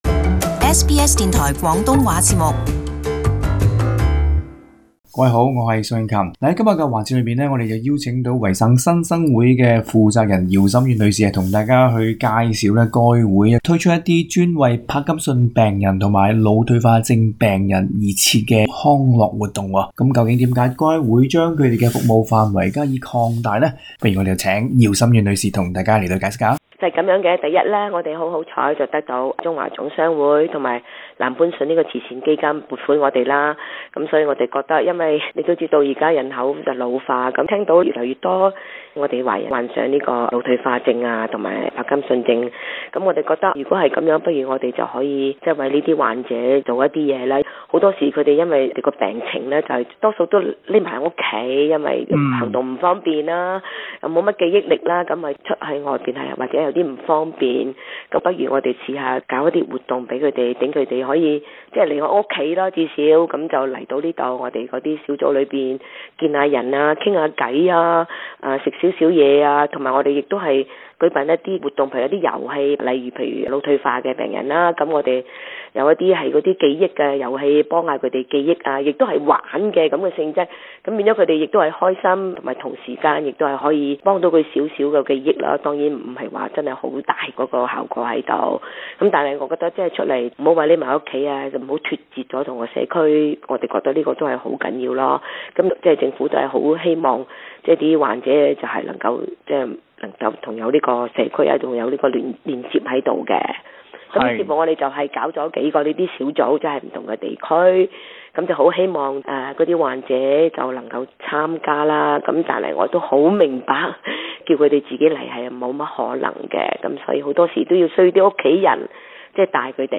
【社區專訪】帕金遜症病人需要什麼